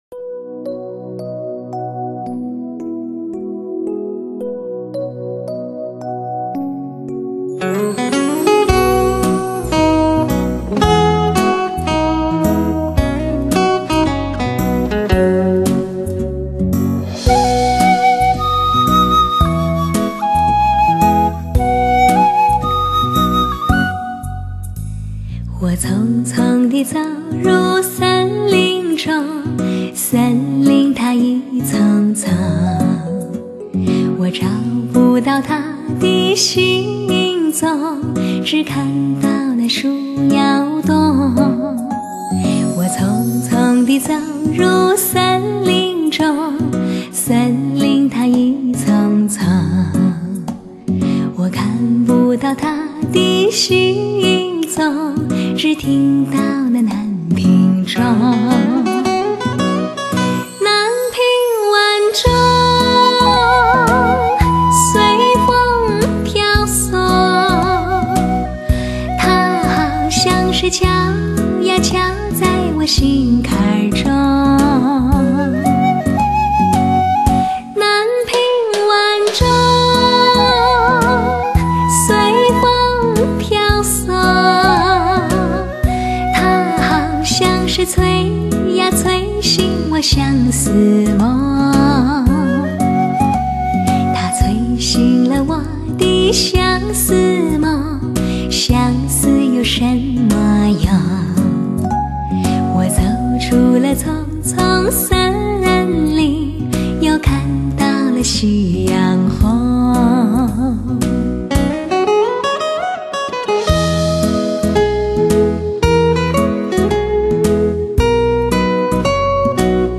美妙的歌喉唱响那记忆中的旋律，